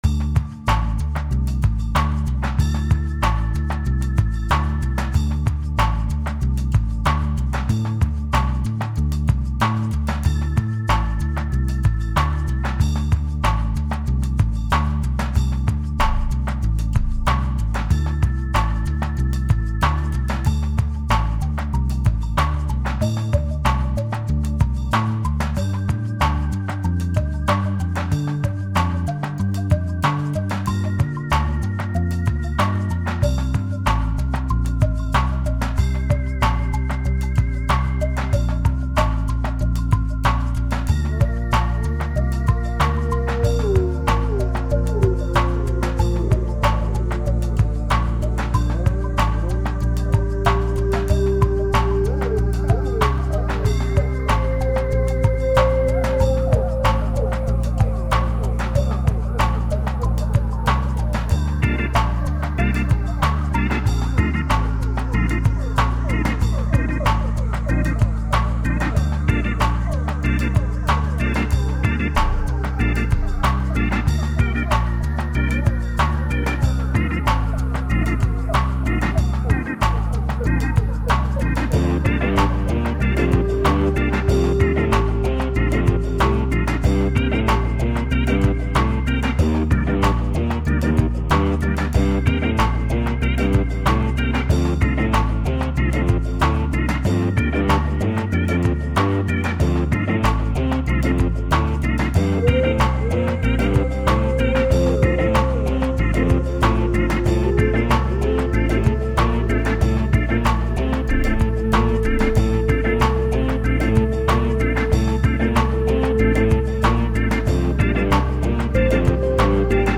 Toutes les constructions sont à priori possibles, j'ai choisi de commencer par l'aspect rythmique, puis mélodique, puis harmonique.